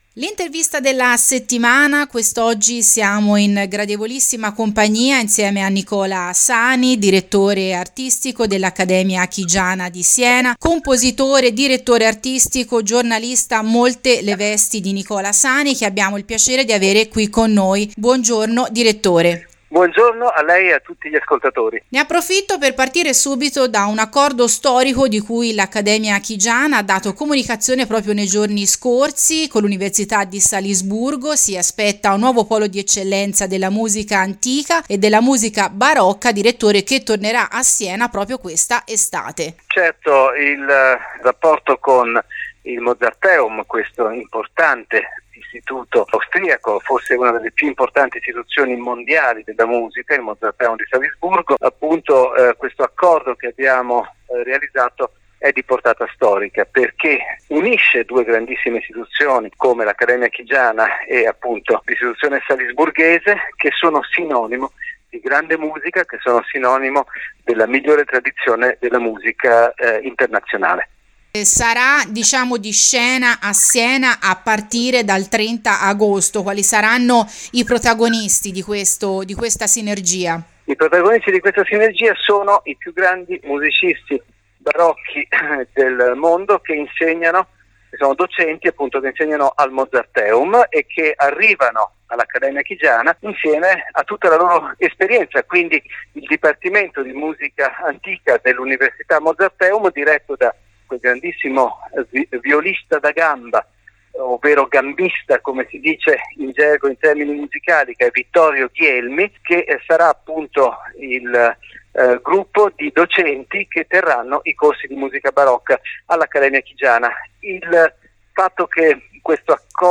Sotto l’intervista completa